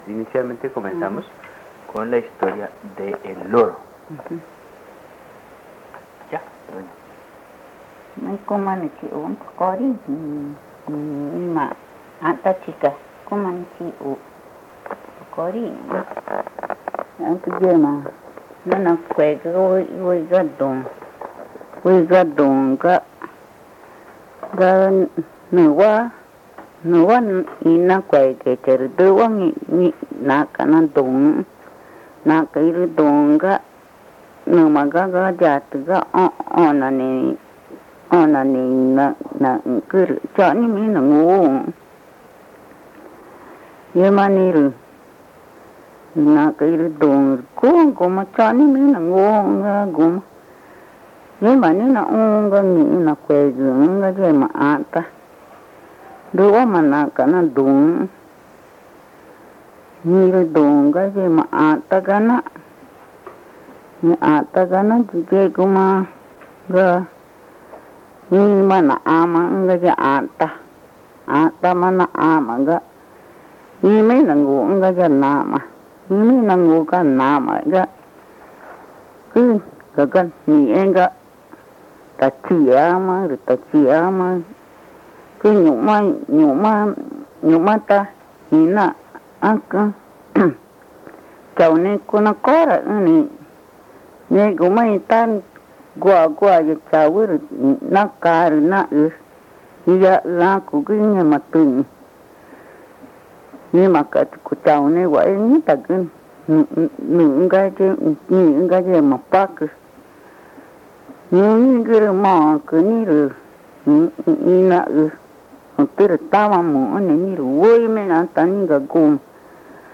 Río Amazonas (Colombia), problamente Pozo Redondo